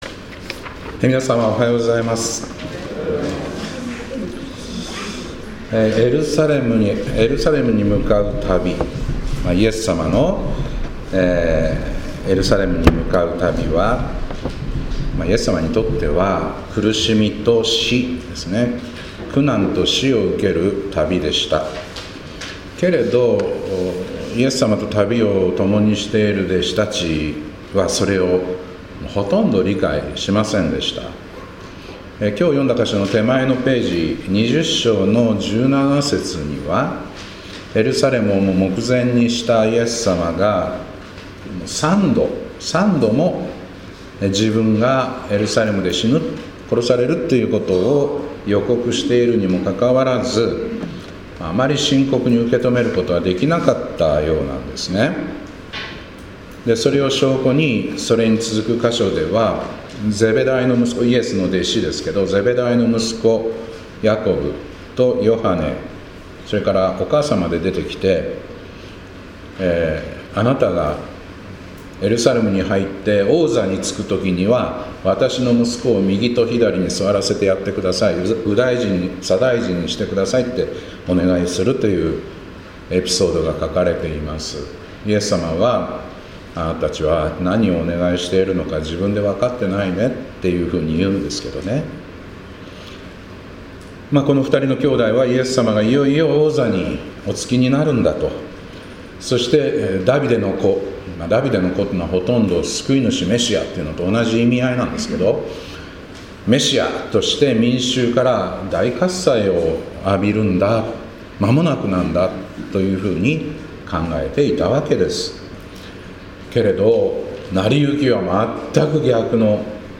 2025年3月16日礼拝「集まって祈り、神に聞く」